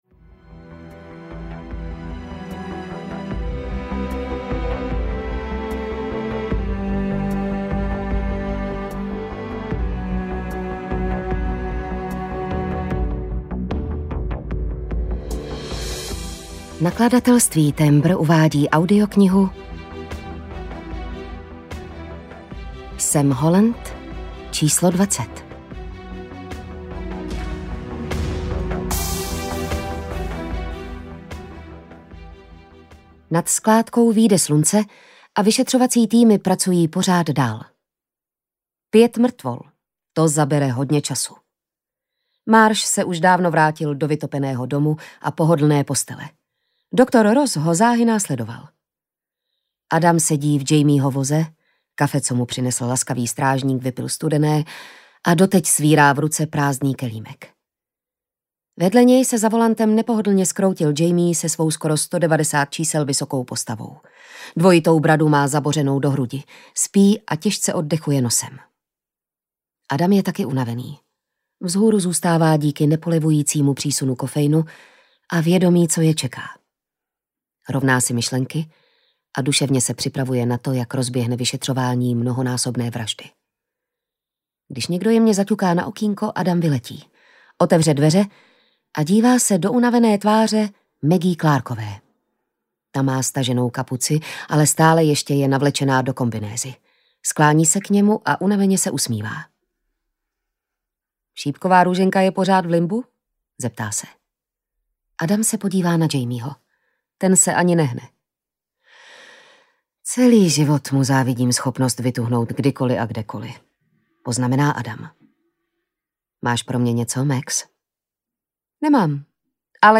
Ukázka z knihy
• InterpretJitka Ježková